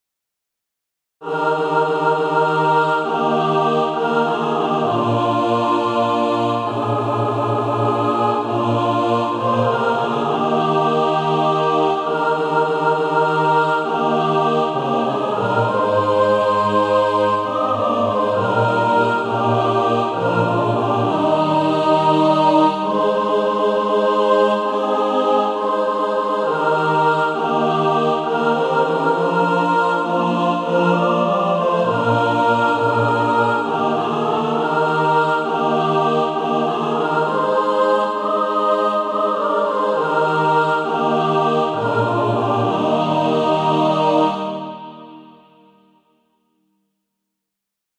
And also a mixed track to practice to
(SATB) Author
Practice then with the Chord quietly in the background.